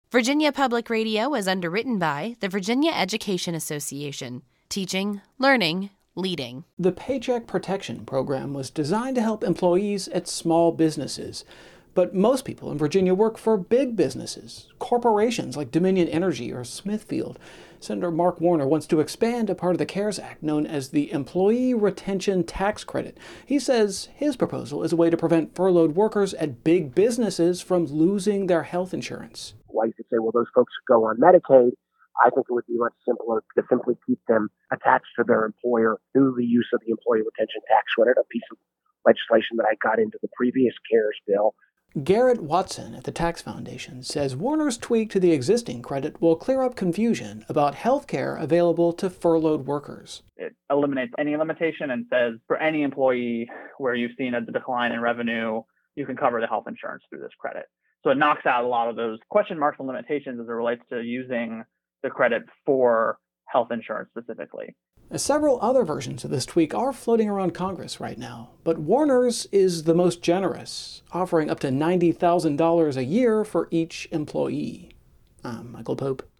Virginia's News